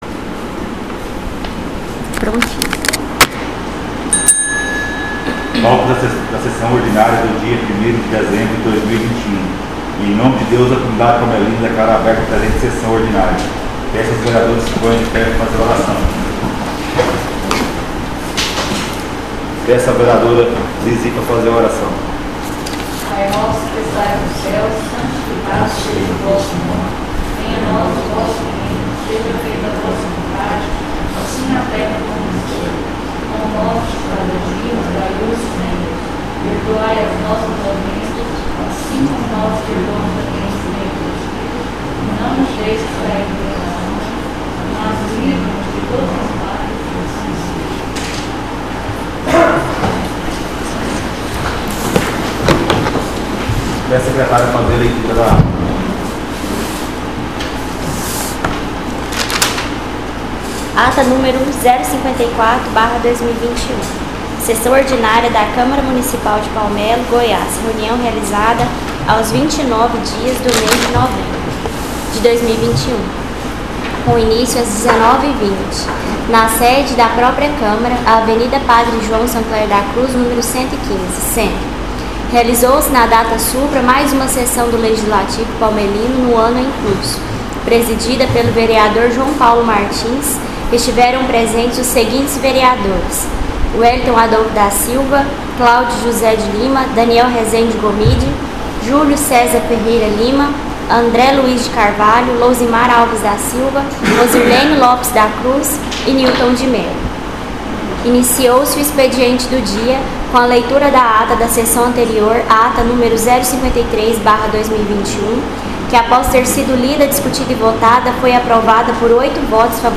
SESSÃO ORDINÁRIA DIA 01/12/2021 — Câmara Municipal de Palmelo
SESSÃO ORDINÁRIA DIA 01/12/2021